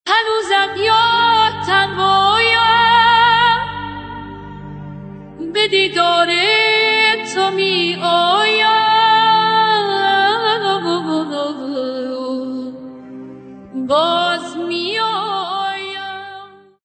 ساخته شده با هوش مصنوعی